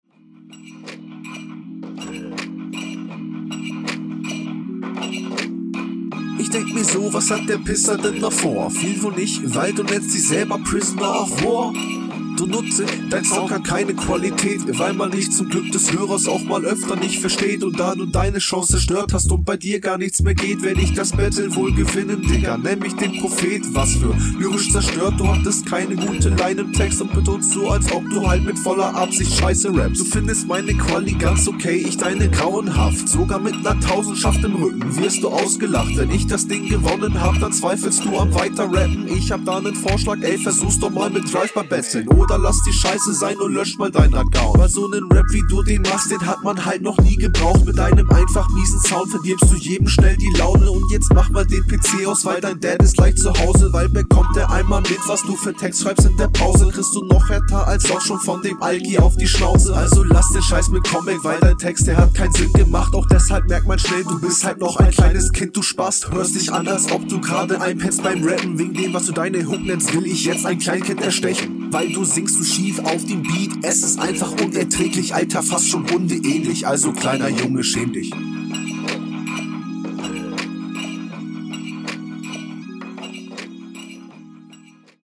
Battle Rap Bunker
Deutlich angenehmer flow und weniger unnötige atmer zwischendurch.